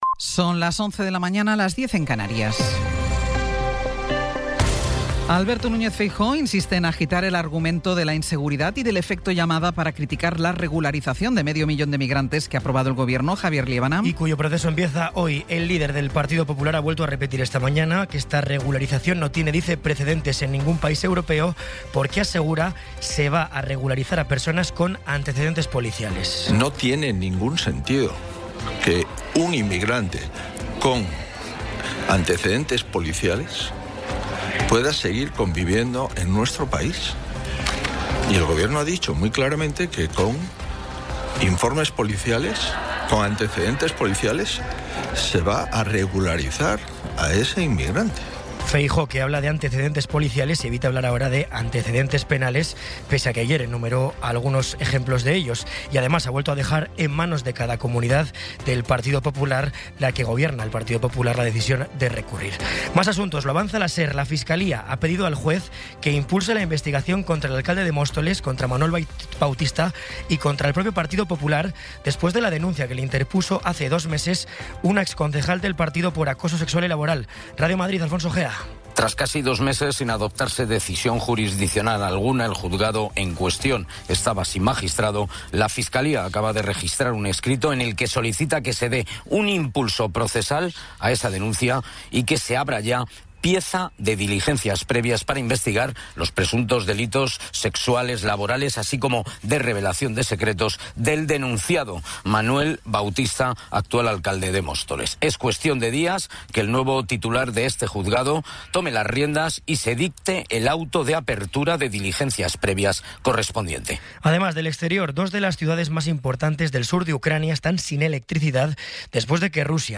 Resumen informativo con las noticias más destacadas del 16 de abril de 2026 a las once de la mañana.